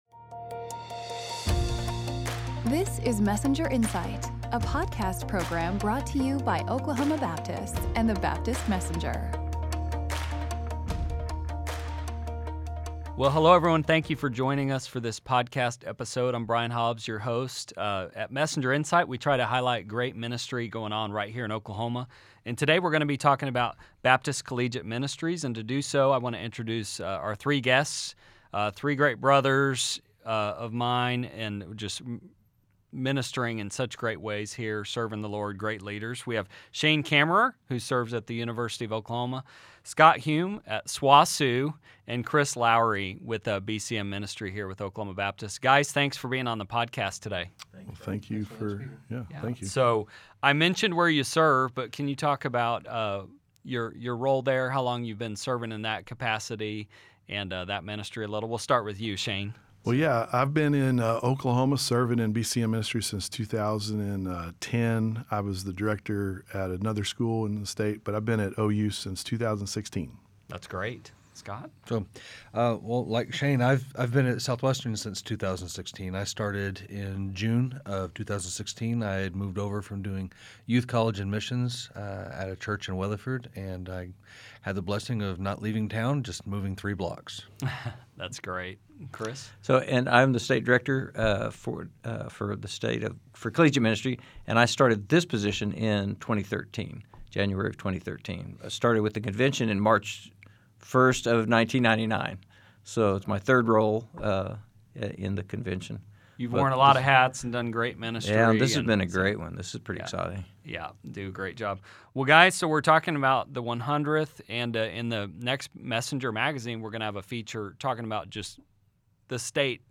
This year marks the 100th Anniversary of Baptist Collegiate Ministries. Hear Oklahoma BCM leaders talk about the Gospel impact of BSU/BCM ministry through the years to today.